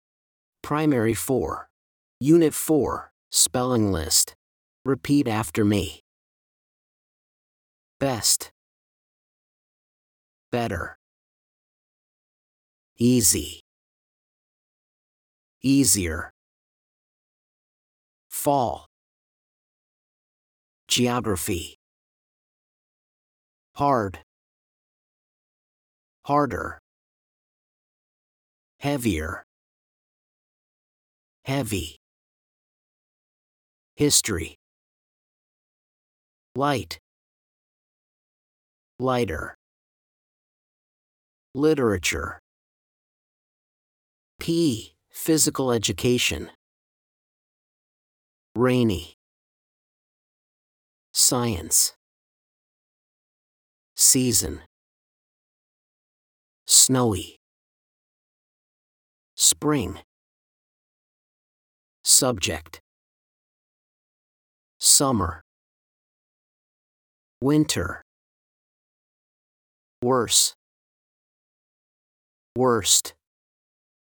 blankThese are the words on the spelling list. Listen and repeat after the teacher: